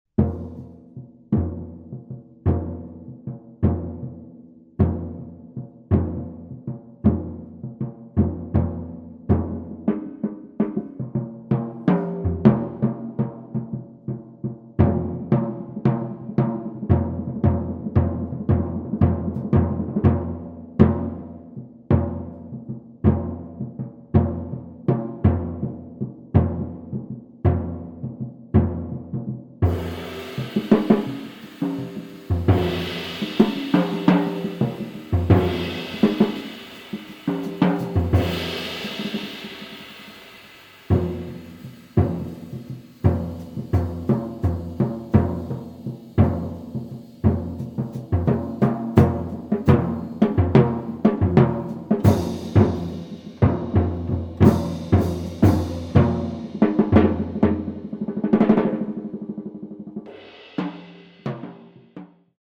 sax
piano
bass
drums